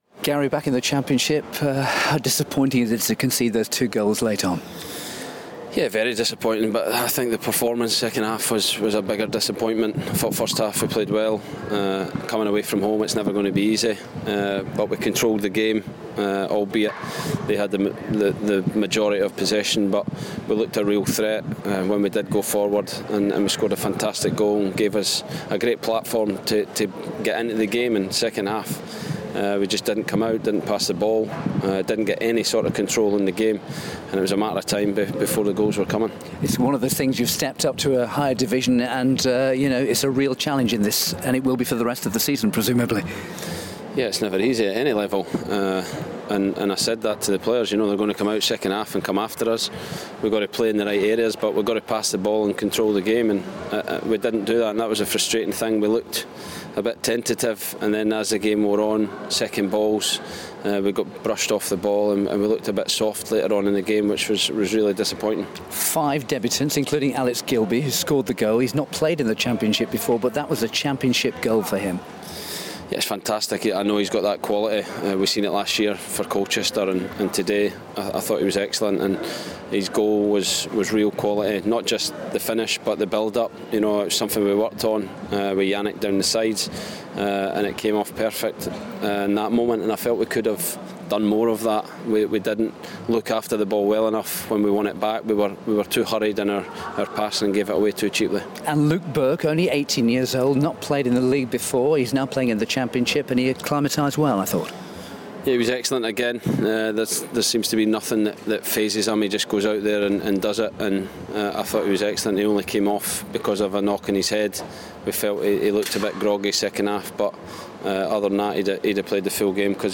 Wigan's Gary Caldwell speaks following 2-1 defeat to Bristol City on the opening day of the season.